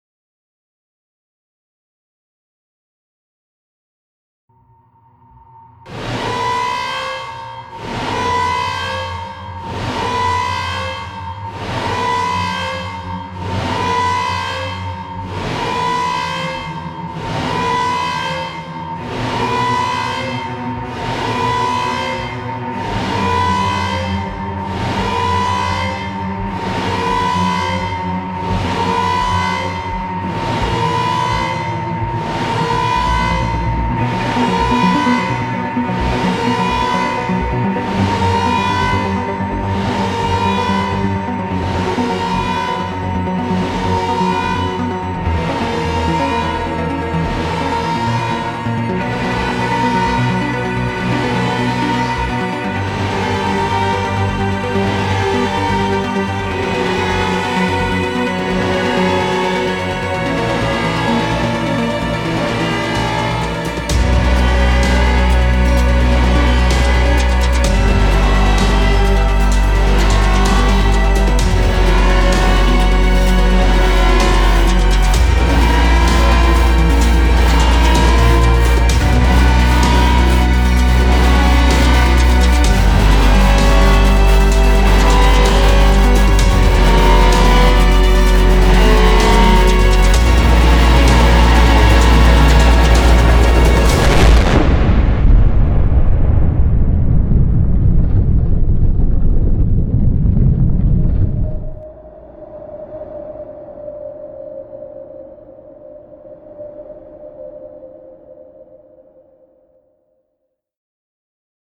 Dead_Mans_Sequence_Alarm.mp3